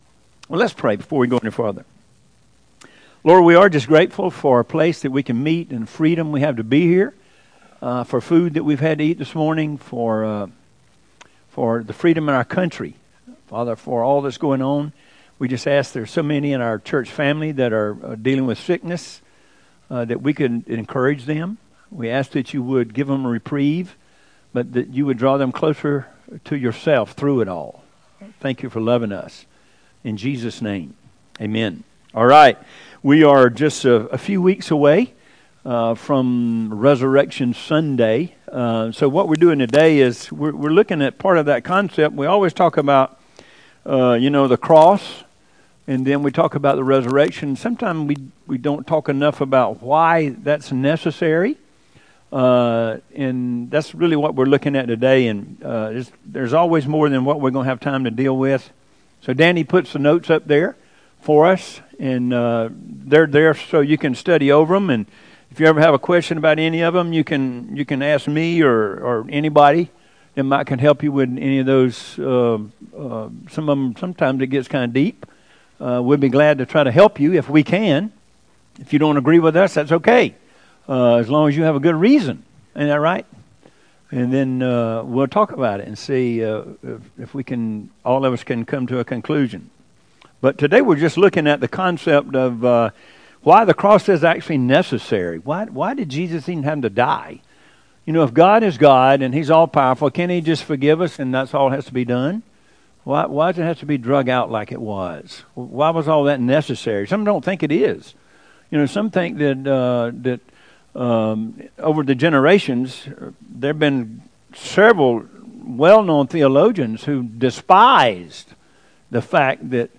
0406Sermon.mp3